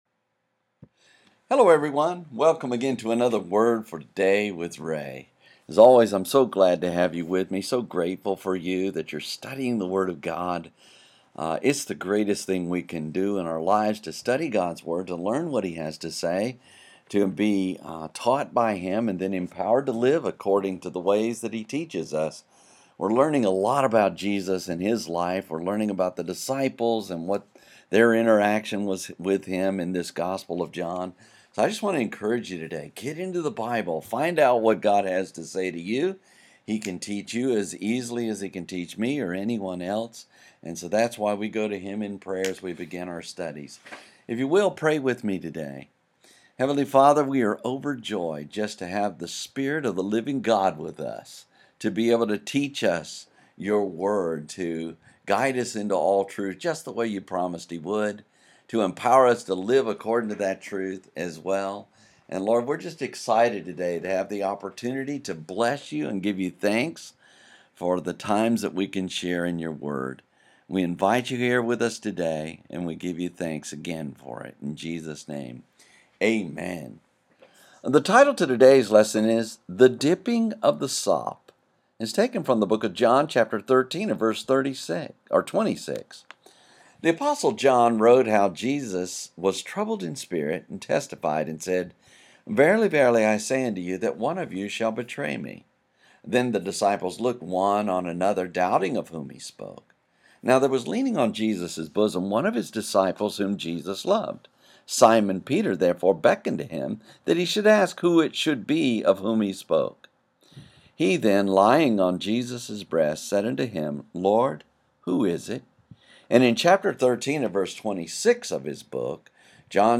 Verse by verse study through the book of John Chapter Thirteen and Verse Twenty Six